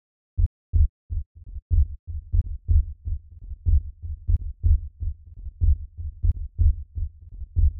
• tech house bass samples 2 - Em - 123.wav
tech_house_bass_samples_2_-_Em_-_123_0FT.wav